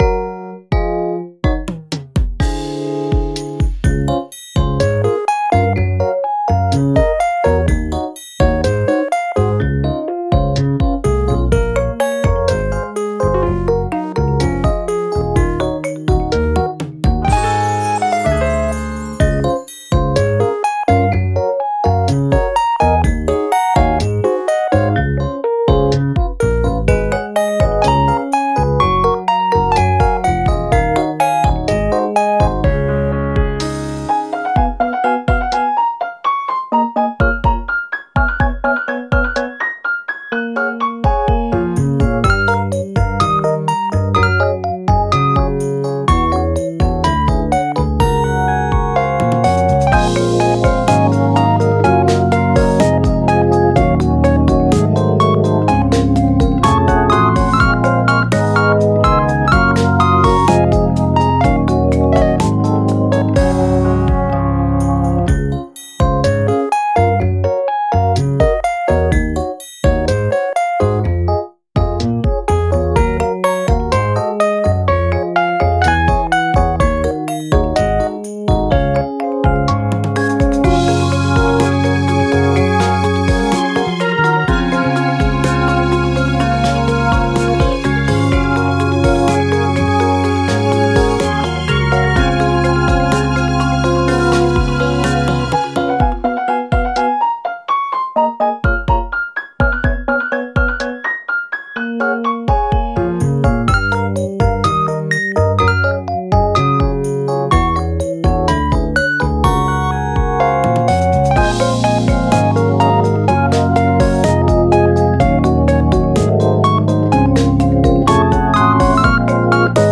ループ用音源（BPM=125）